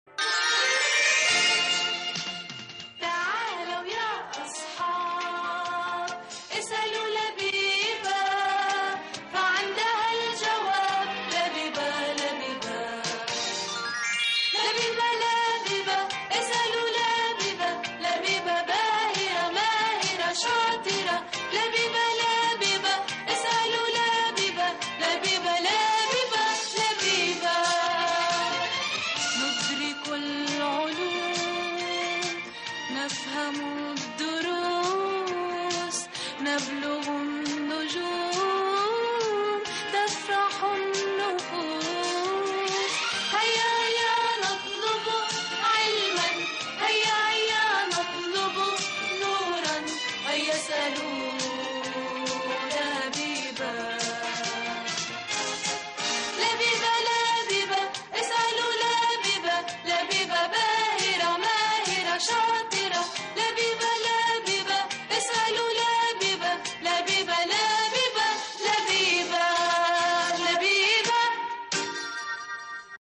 اسألوا لبيبه - الحلقة 1 مدبلجة